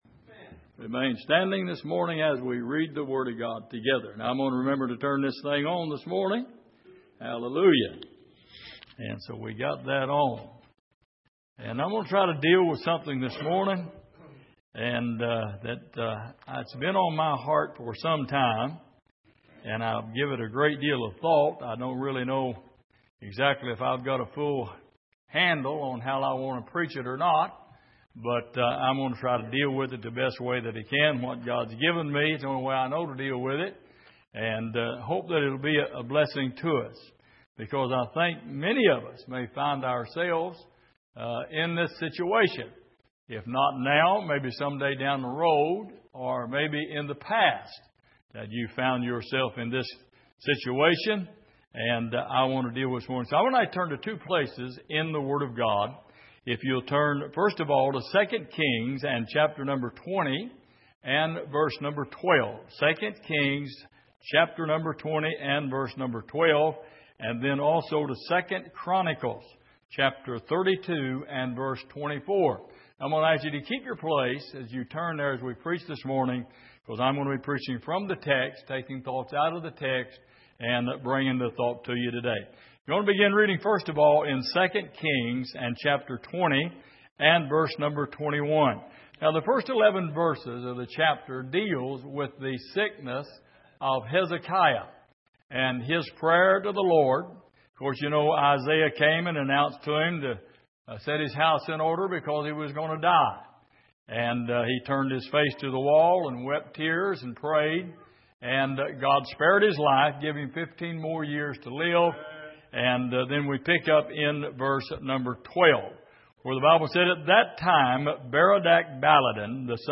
Passage: 2 Kings 20:12-19 Service: Sunday Morning